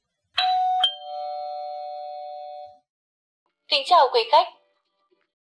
Tiếng nói Kính Chào Quý Khách! (Giọng Nữ, Mẫu số 3)
Thể loại: Tiếng chuông, còi
tieng-noi-kinh-chao-quy-khach-giong-nu-mau-so-3-www_tiengdong_com.mp3